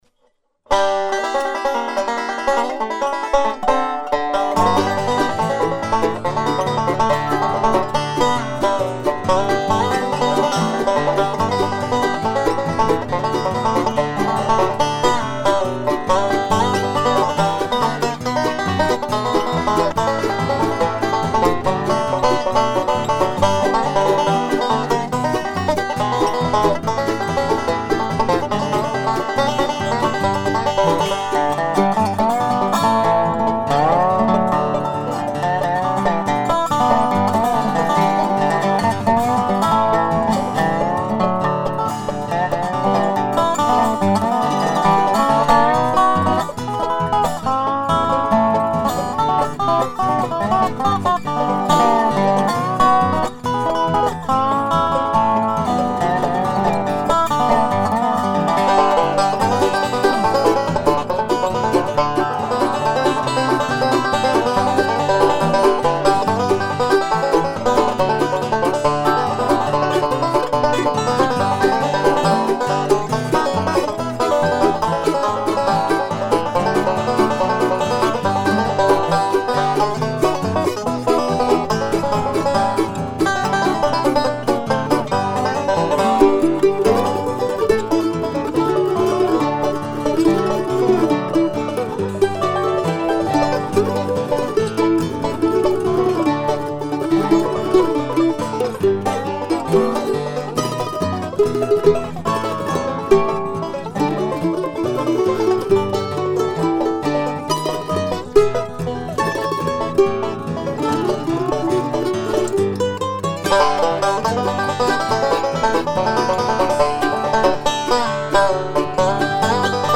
Bluegrass Instrumental
I put all my favorite banjo licks together and made an instrumental.